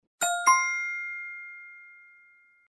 Звуки победы, салюта